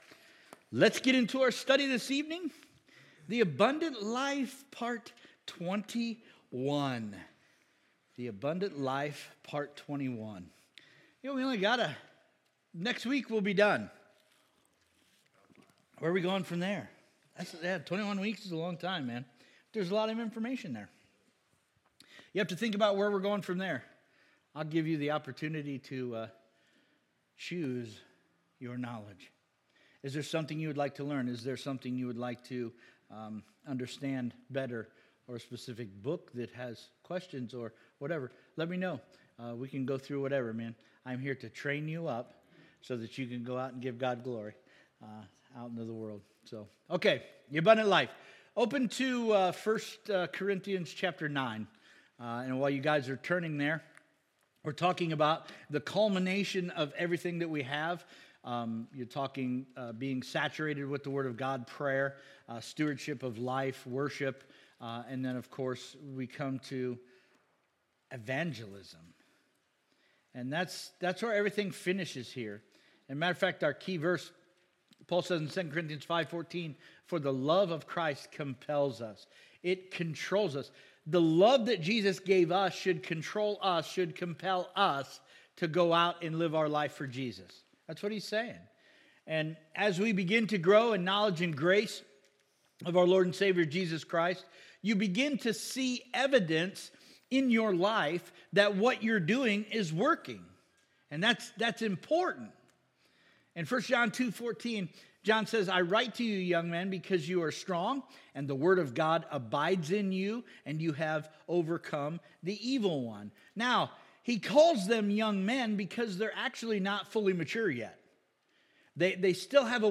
Sermons Archive - Page 9 of 51 -